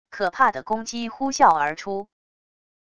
可怕的攻击呼啸而出wav音频